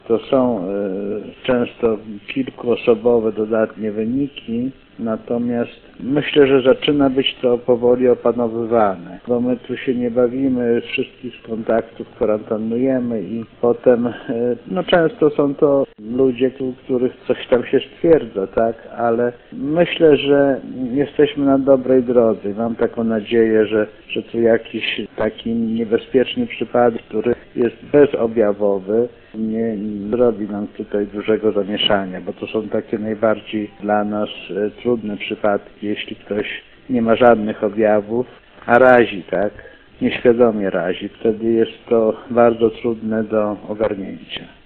Jerzy Jan Nikliński, szef grajewskiego sanepidu ocenia, że sytuacja w powiecie jest pod kontrolą.